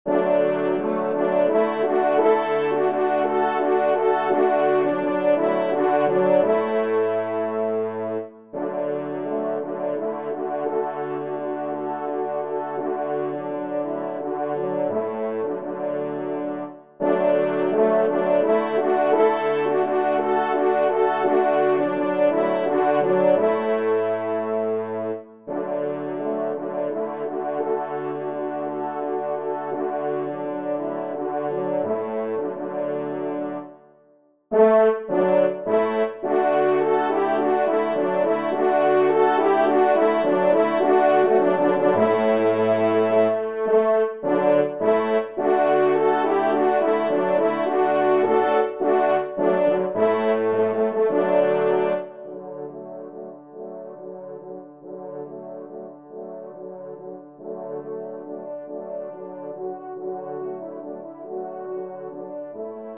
ENSEMBLE